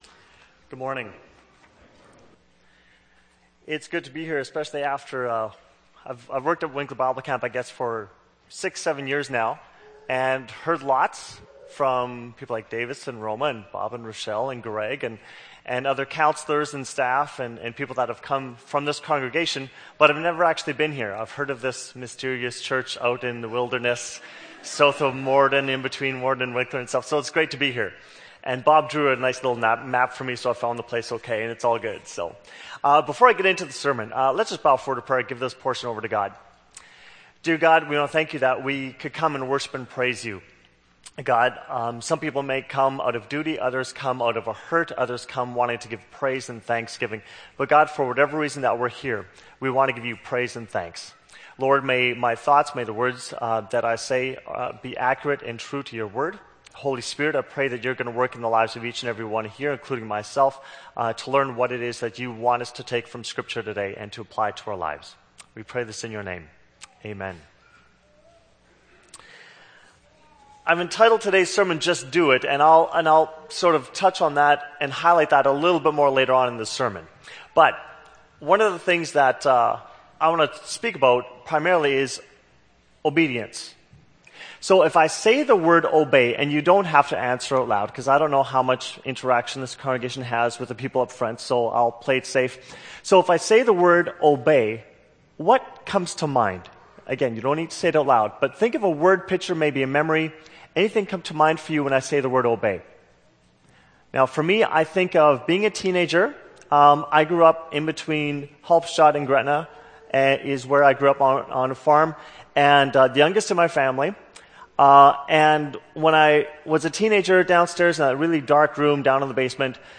April 10, 2011 – Sermon – Glencross Mennonite Church
April 10, 2011 – Sermon